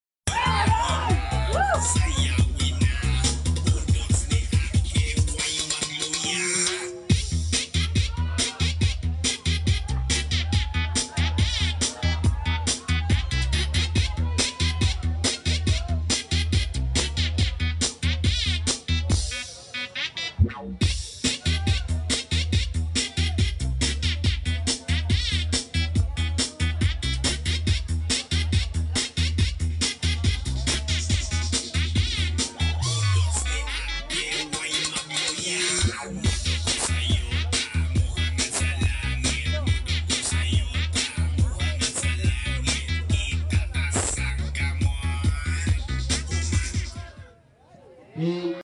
Budots